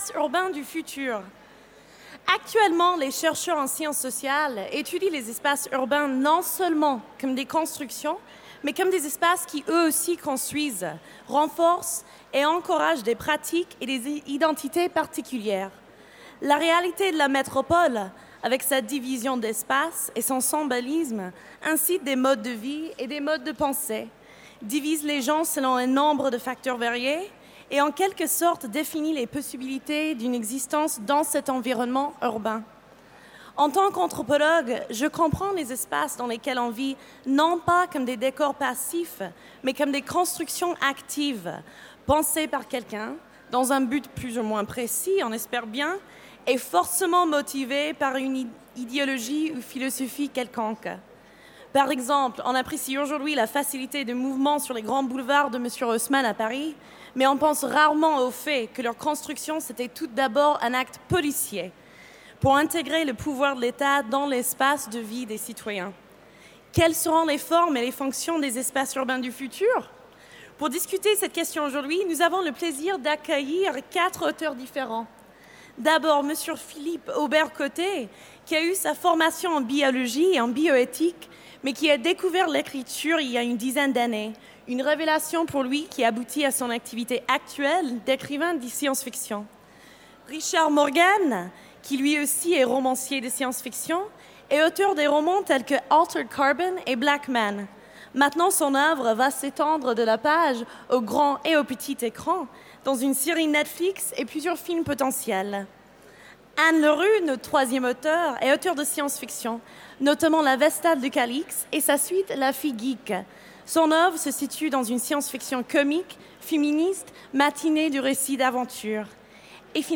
Utopiales 2017 : Conférence Espaces urbains du futur